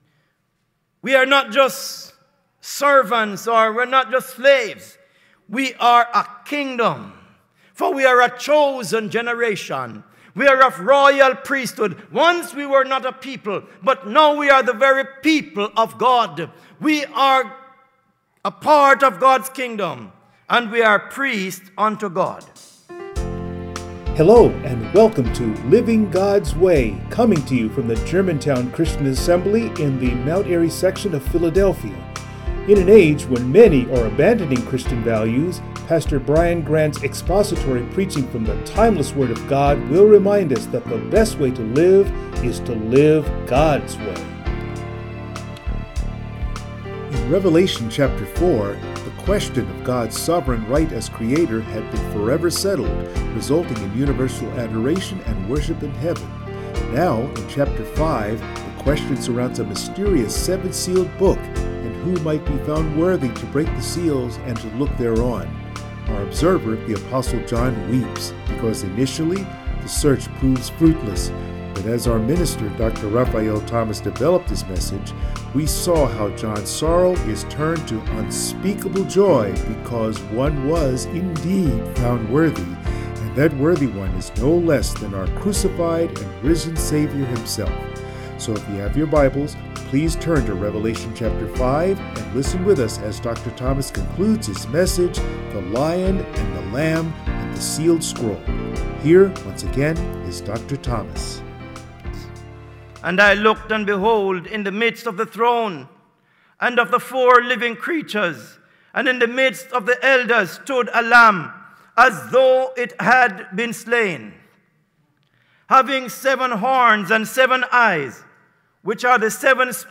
Passage: Revelation 5 Service Type: Sunday Morning